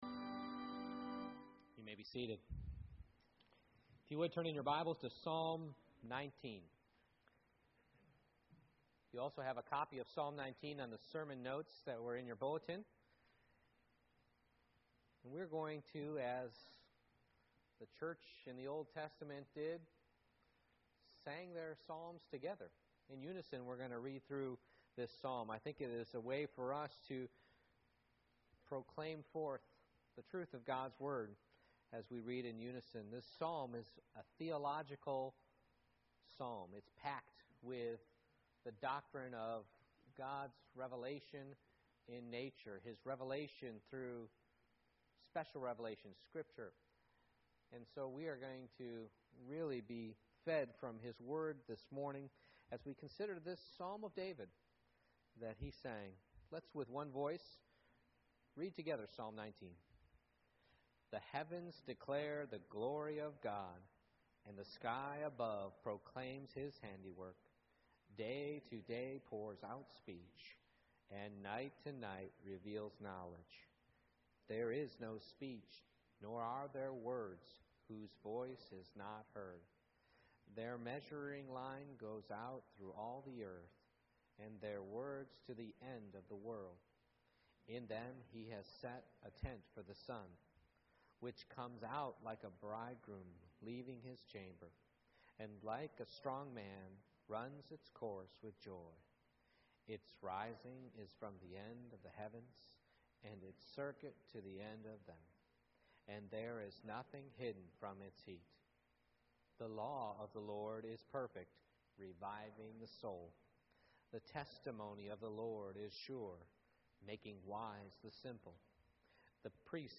Service Type: Morning Worship God's Glory in the Heavens How can I get the most out of studying God's glory in Creation?